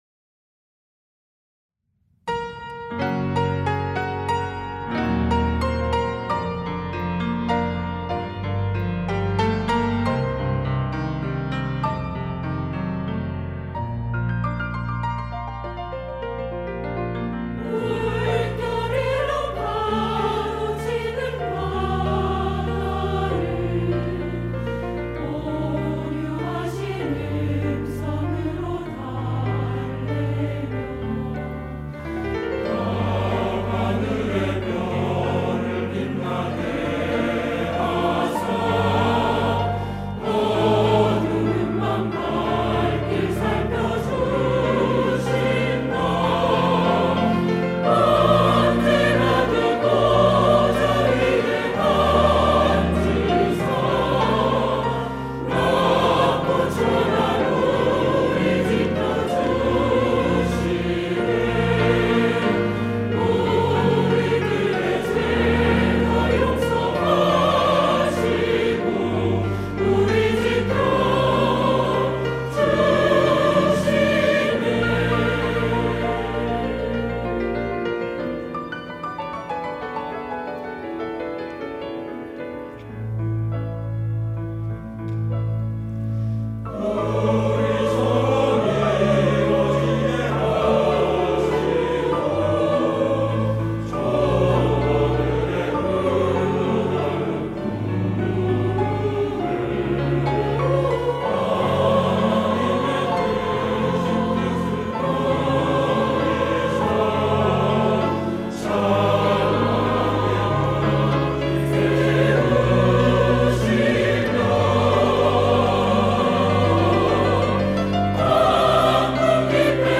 시온(주일1부) - 주님
찬양대